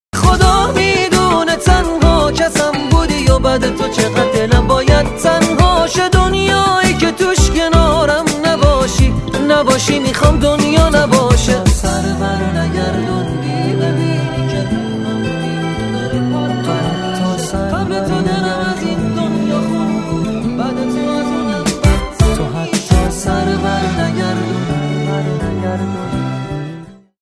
رینگتون احساسی و باکلام
موسیقی پاپ